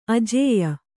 ♪ ajēya